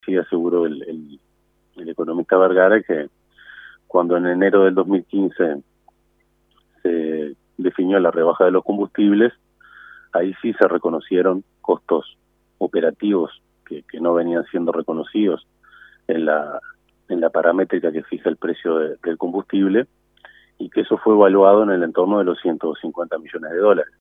Por su parte, Otheguy, presidente de la comisión investigadora, dijo a 810 Vivo que se comprobó lo que el directorio de Ancap había dicho en la comisión respecto a que entre 2011 y 2015 no se tuvieron en cuenta gastos de distribución, que generaban pérdidas operativas, en la fijación de los precios de los combustibles